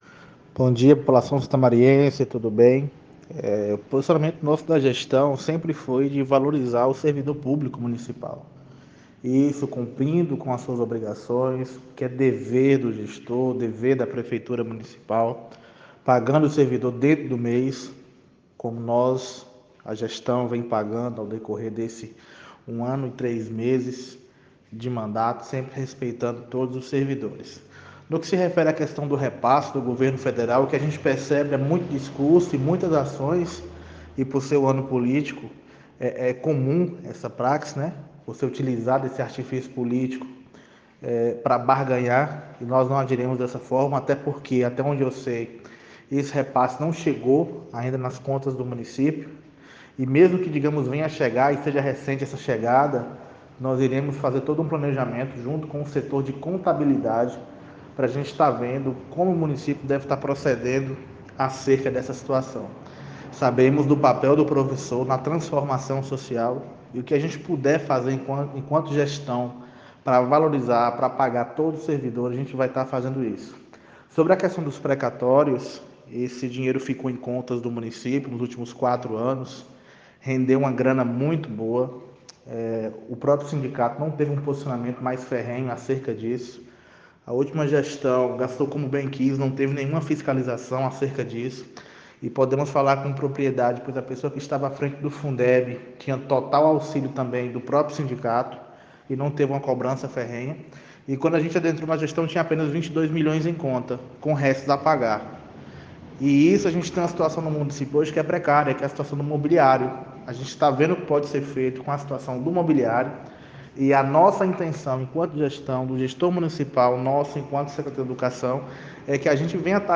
Perguntado sobre o posicionamento da gestão, o Secretário Municipal de Educação respondeu os questionamentos levantados pelo apresentador do noticiário, que foram: